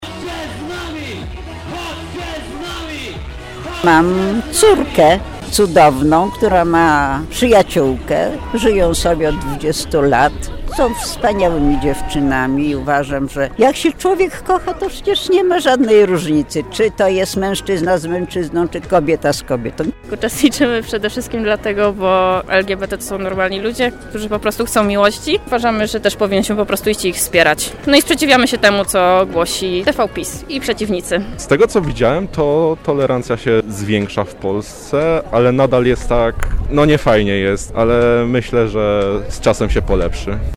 Z uczestnikami wydarzenia rozmawiała nasza reporterka: